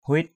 /huɪ:t/ 1.